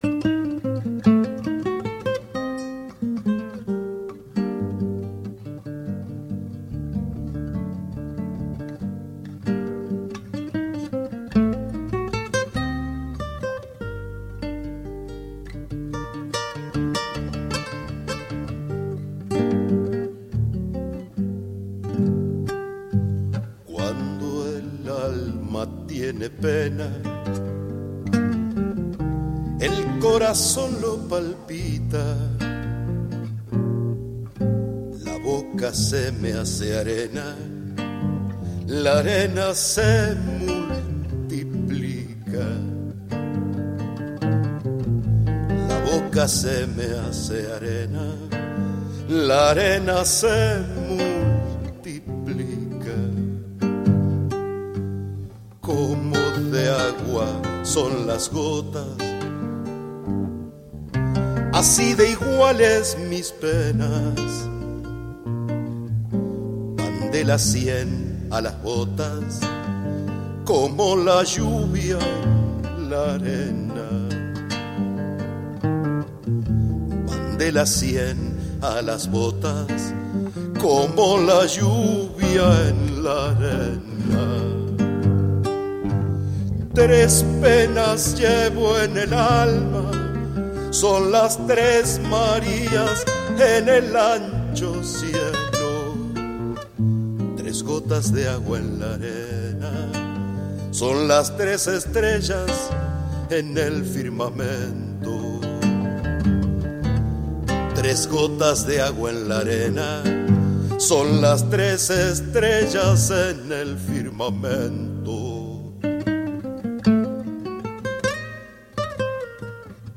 Zamba